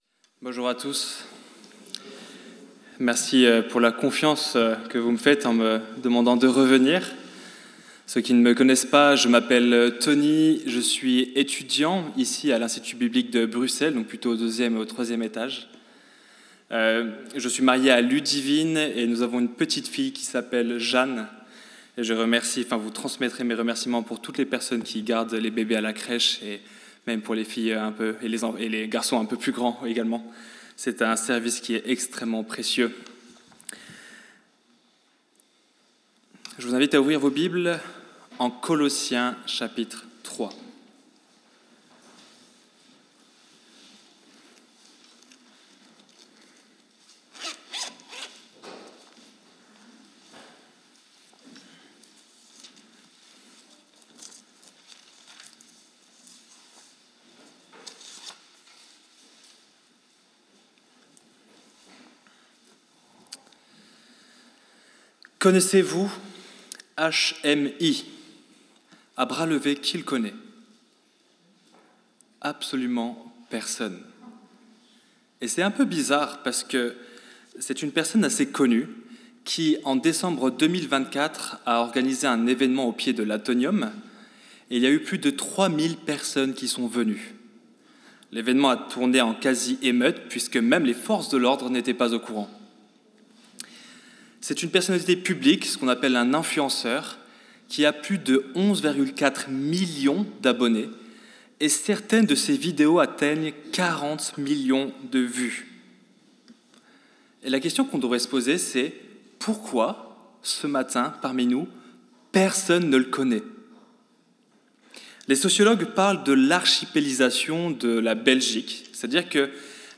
Ecouter le message